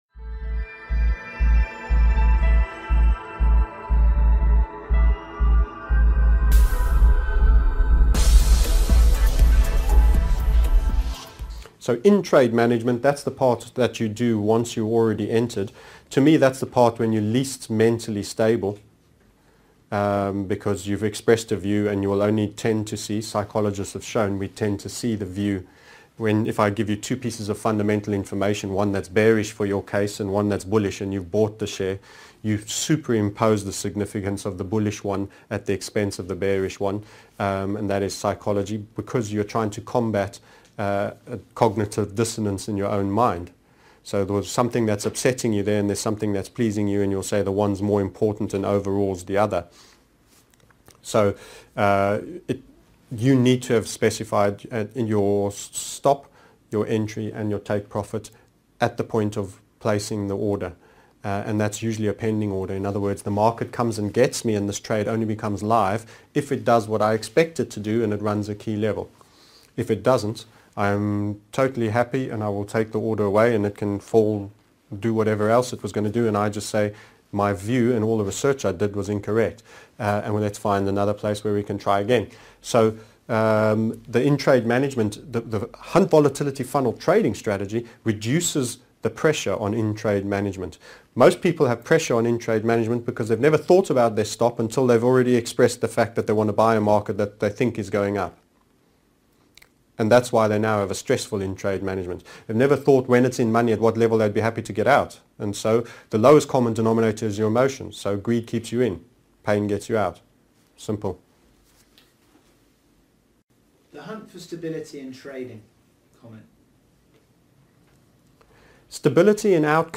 15 What role does trade management play in your trading TMS Interviewed Series 15 of 32